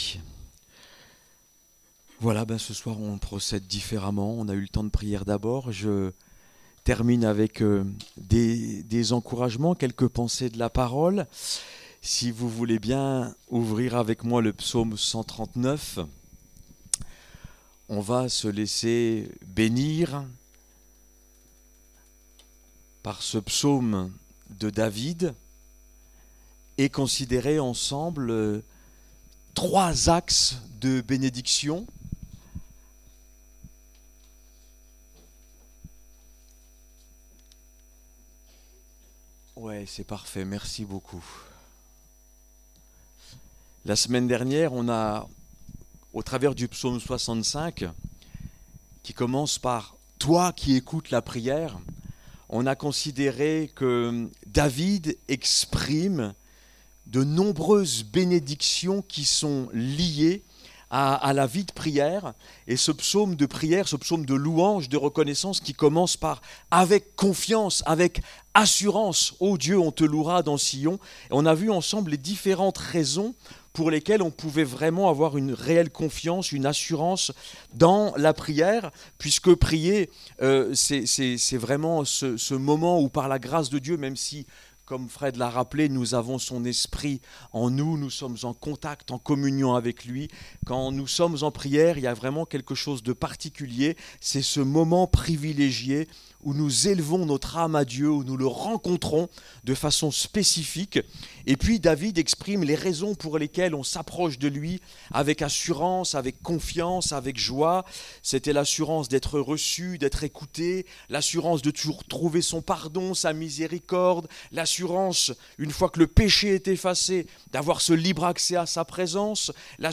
16 décembre 2025 Dieu omniscient,omnipotent,omniprésent Prédicateur